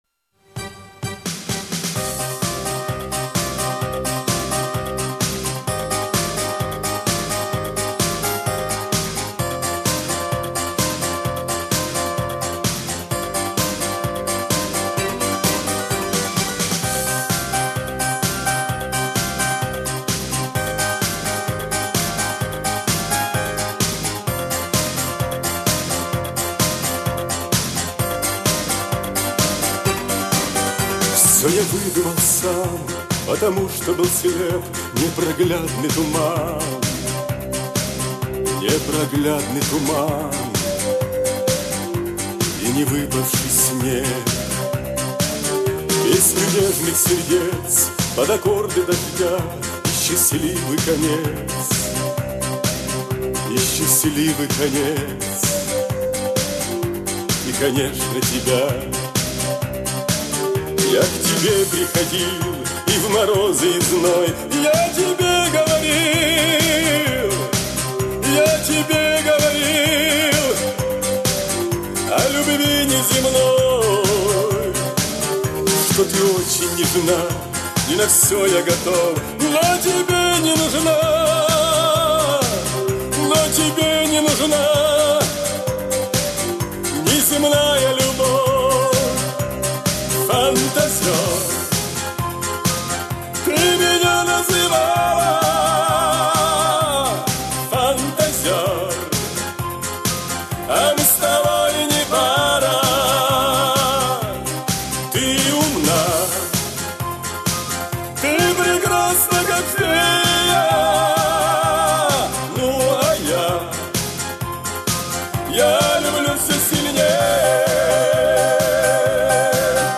Всі мінусовки жанру Dance
Плюсовий запис